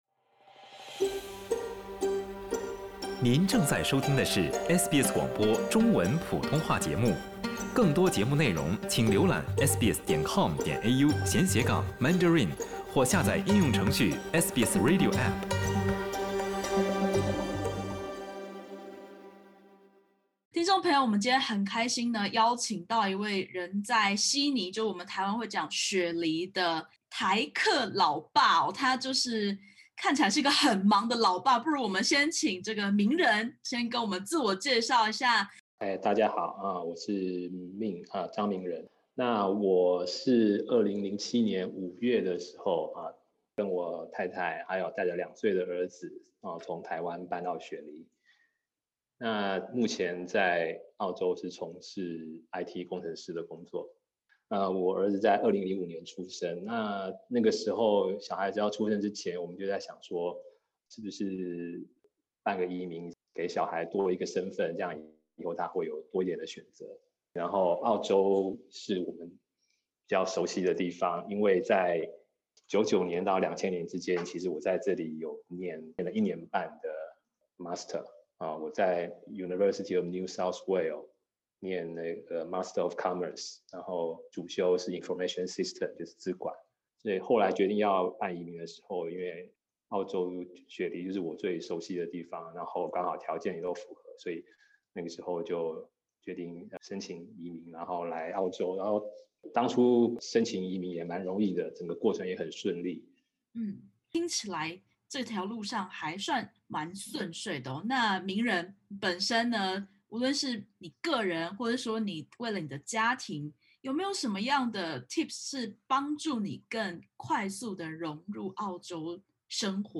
點擊首圖收聽採訪podcast。